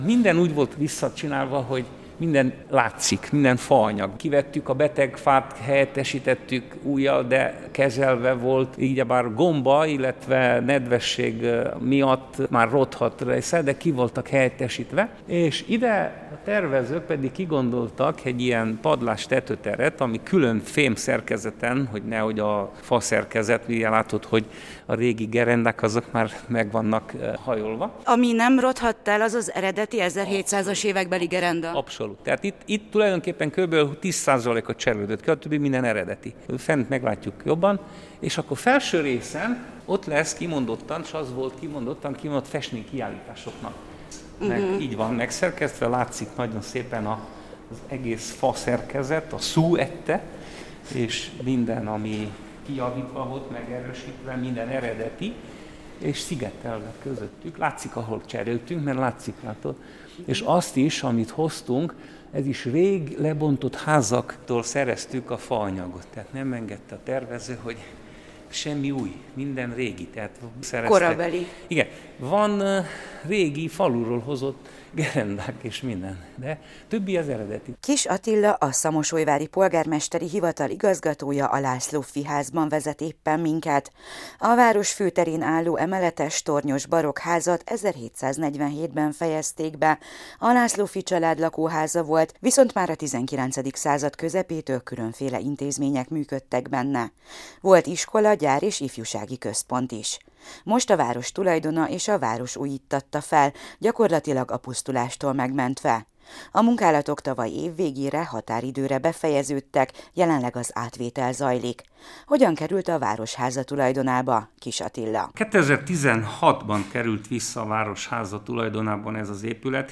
helyszíni interjúja